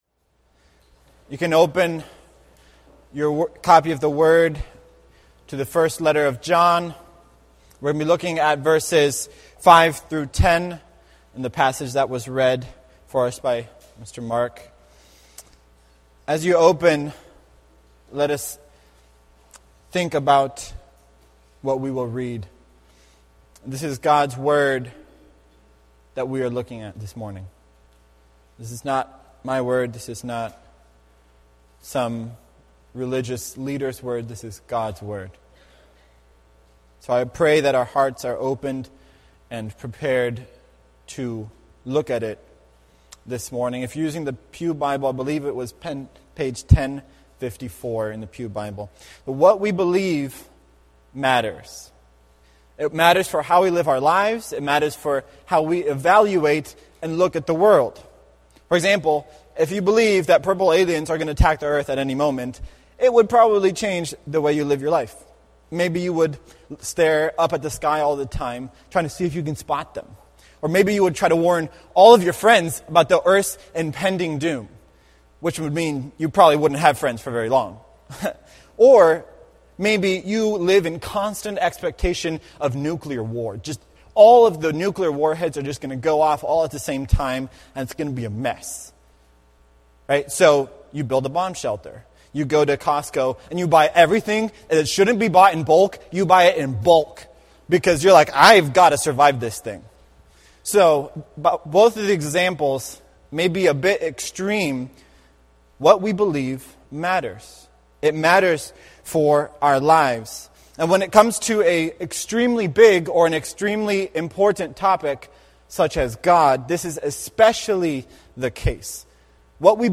2019 Stay up to date with “ Limerick Chapel Sunday Sermons ”